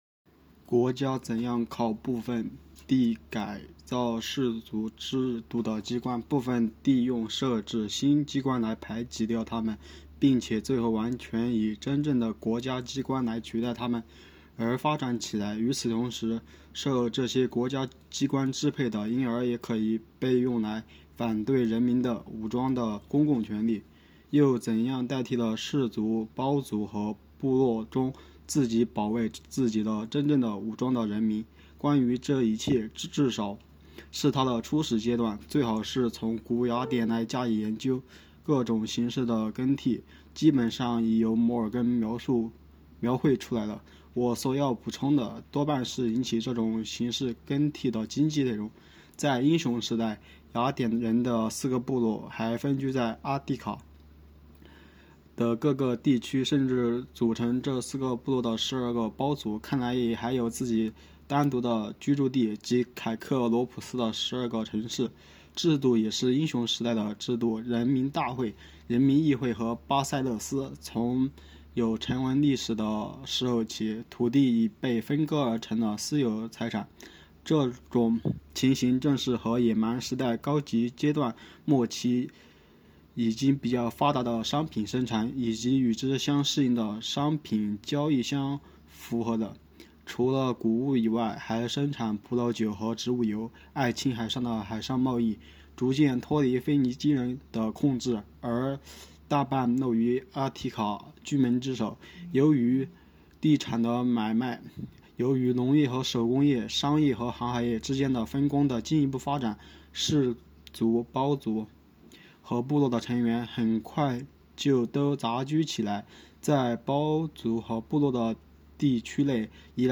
“读经典、悟原理”——2025年西华大学马克思主义经典著作研读会接力诵读（03期）